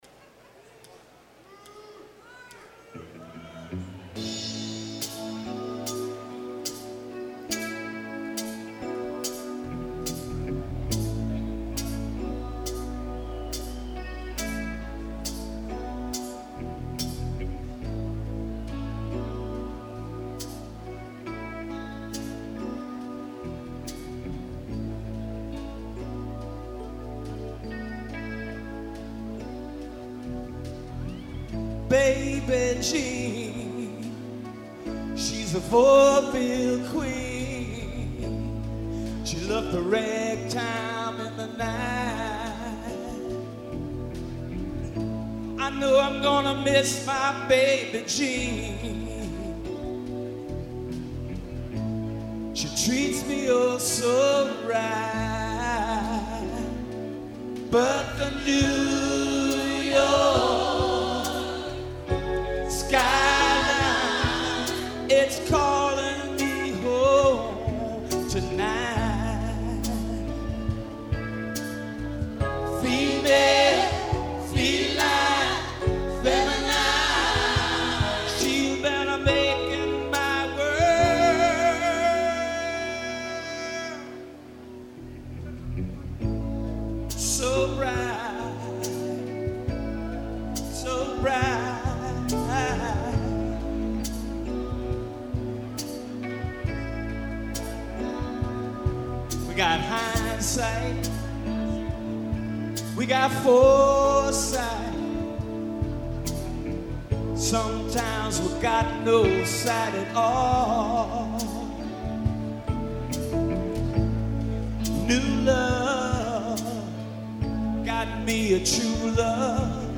guitar
First performed: December 3, 2001 (Asbury Park, NJ)